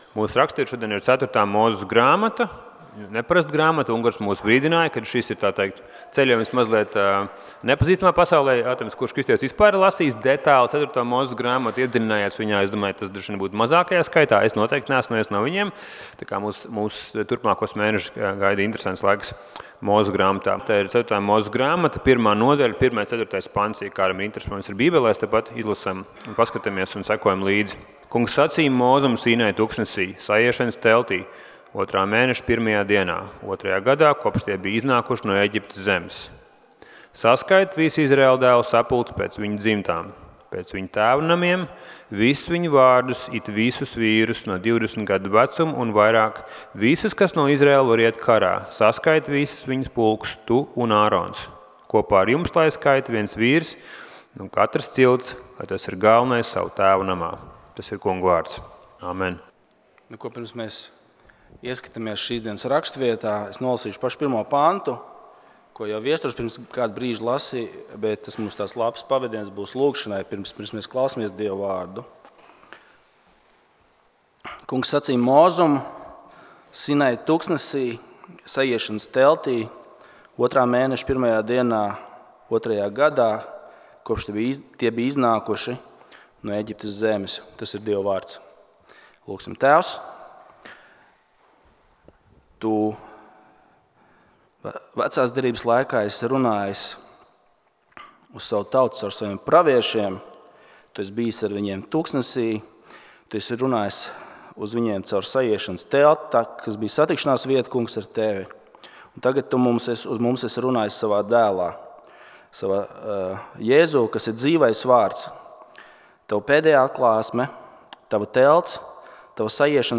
Rīgas Reformātu draudzes svētrunas.